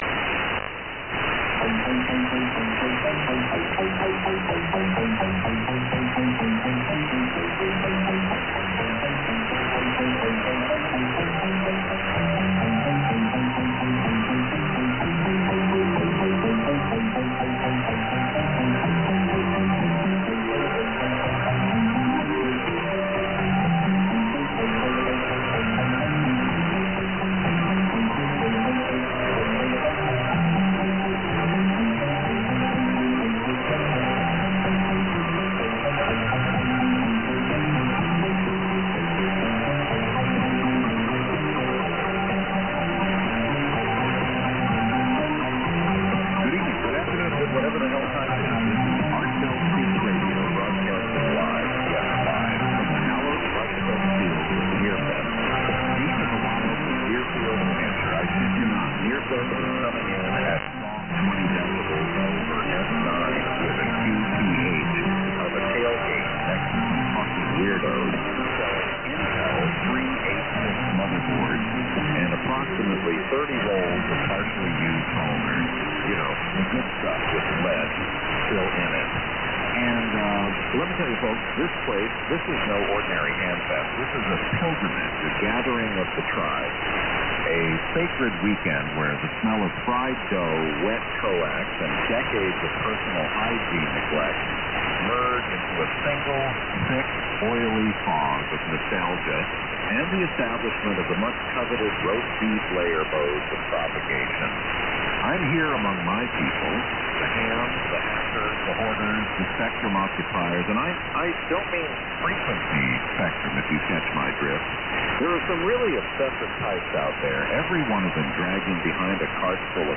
A very entertaining pirate radio broadcaster usually on 6950 USB, this shortwave pirate featured a very realistic AI generated voice of former (now deceased) late night AM radio broadcaster, Art Bell. Themes were mainly comedic and had lots of HFU referenced material.